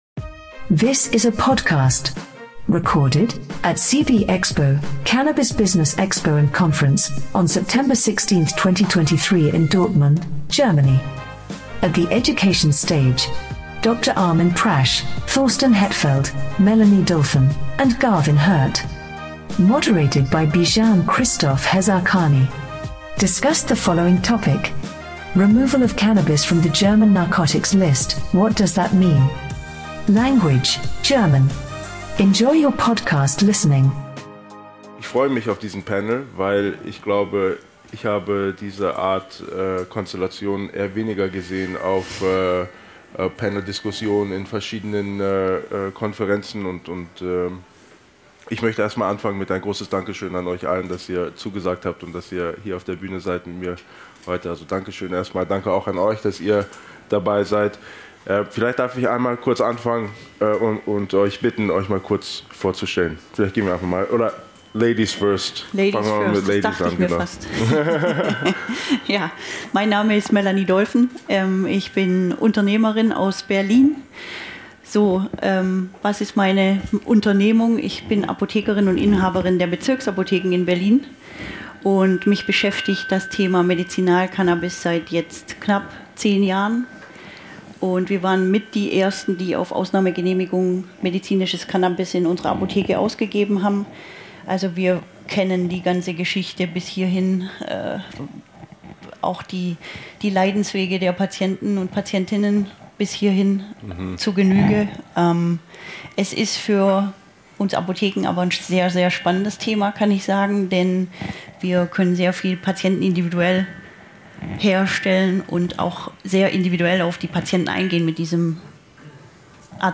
Join us as we introduce a diverse panel, bringing together different perspectives of the industry, to provide their insights on what the removal of cannabis from the German Narcotics List really means.